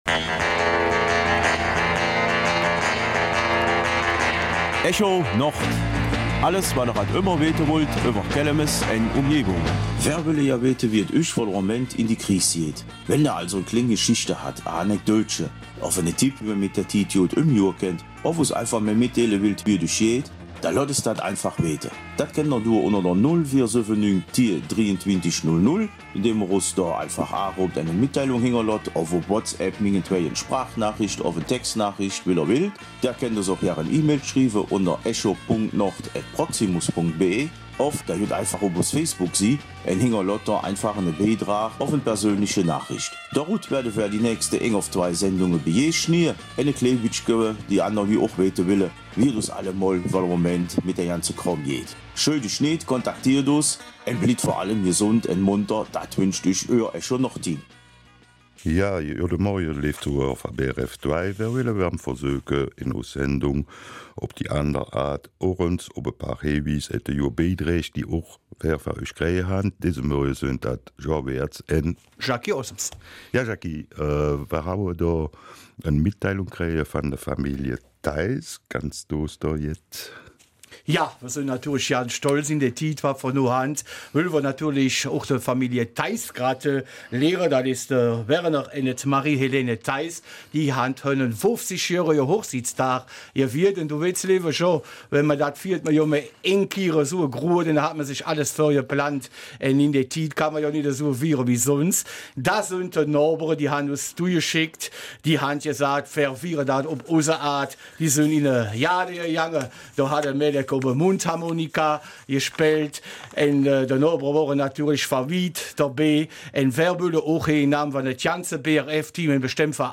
Kelmiser Mundart - 3. Mai: Wie geht es euch?